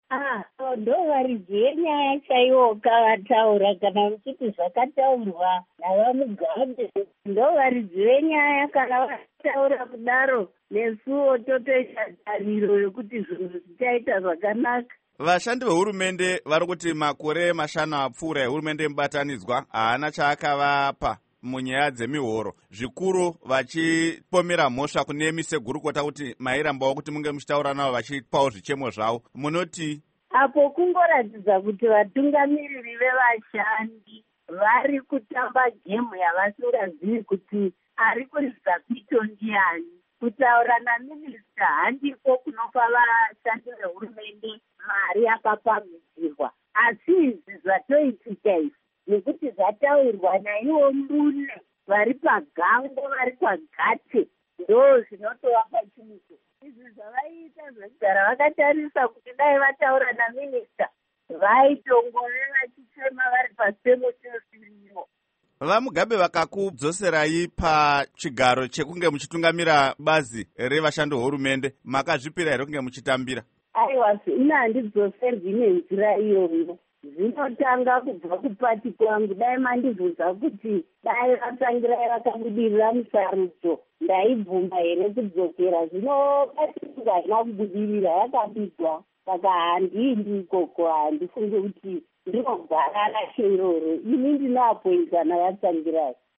Hurukuro naMai Lucia Matibenga